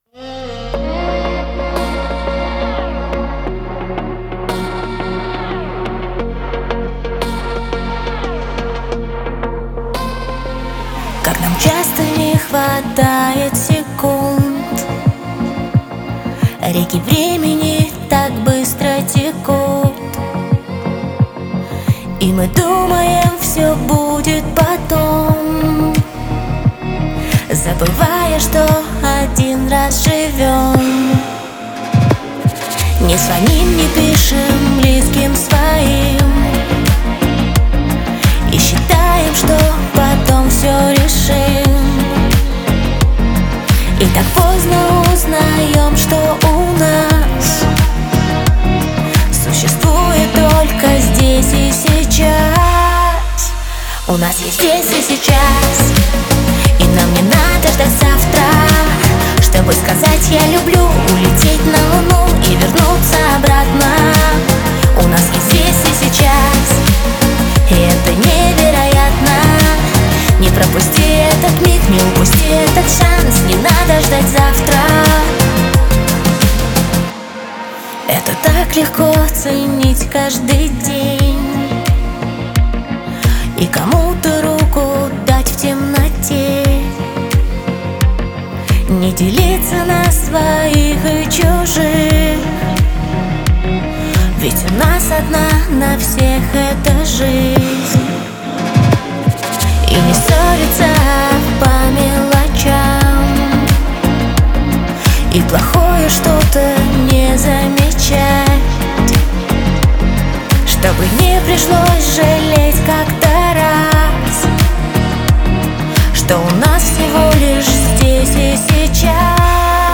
Жанры: поп-рок, электропоп, баллада,
эстрада, блюз, танцевальная музыка